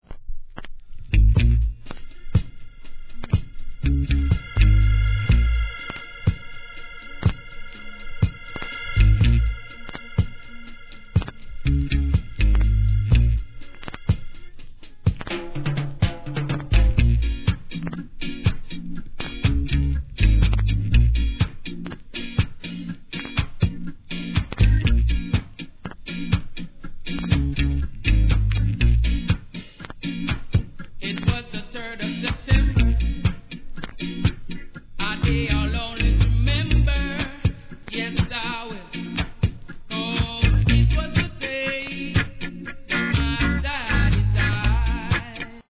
スクラッチあるため周期的なノイズが中盤まで入ります
REGGAE